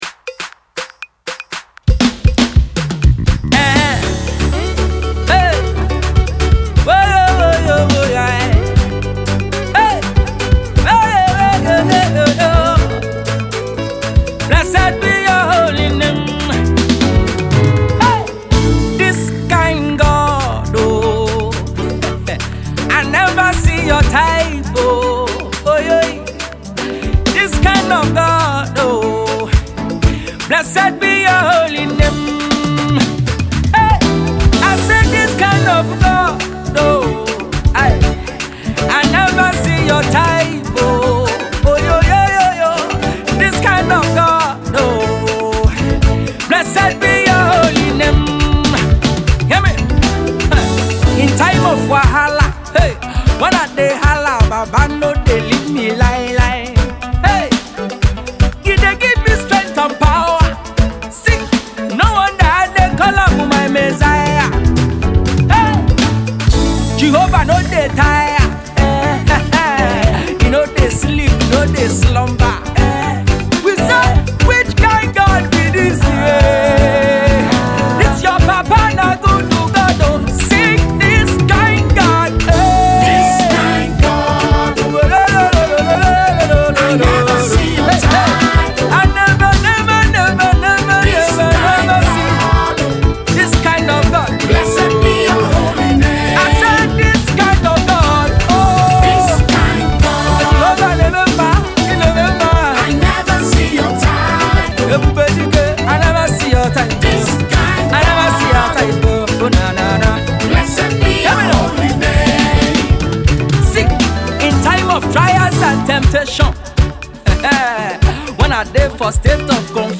melodious song of praise
Drums
Guilter